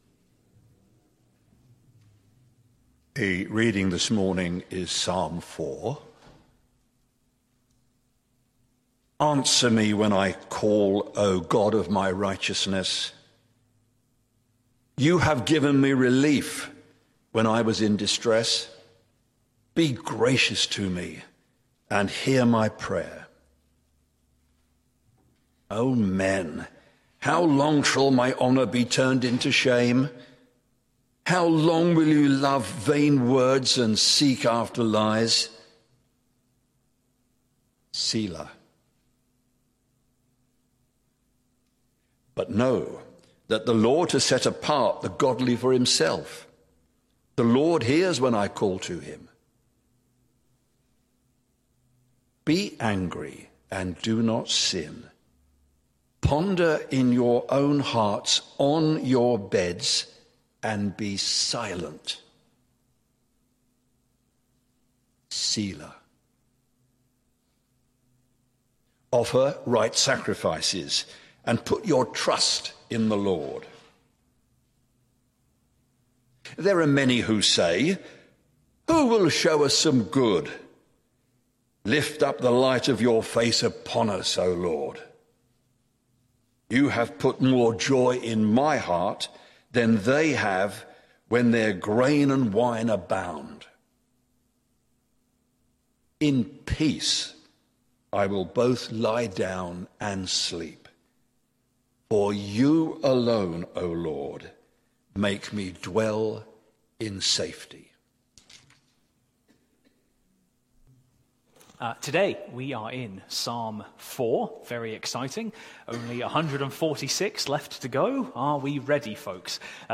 Sermon Series: The Psalms